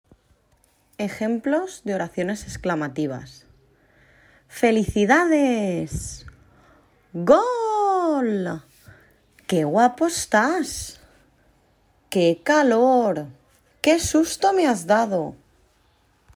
Tienen una entonación muy distinta a las oraciones interrogativas.
ejemplos_exclamativas.mp3